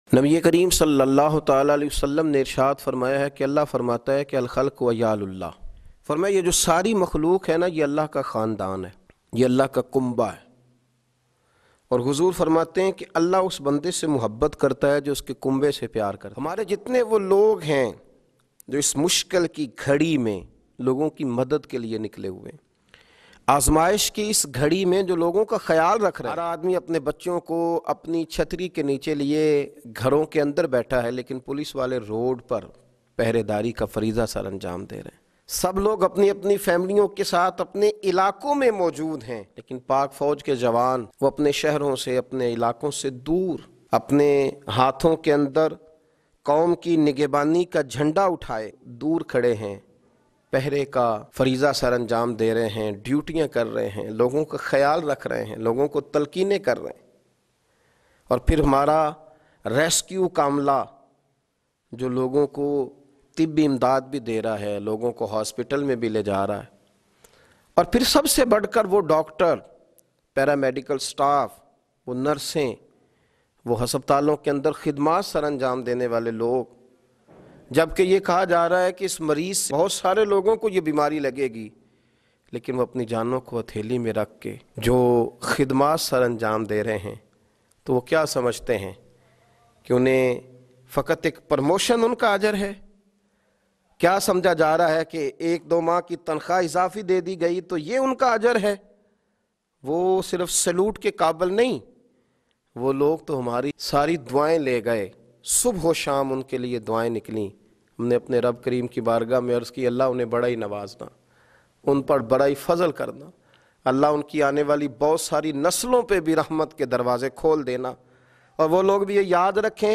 Khidmat Karny Walo Ko Salam Bayan MP3 Download in best audio quality.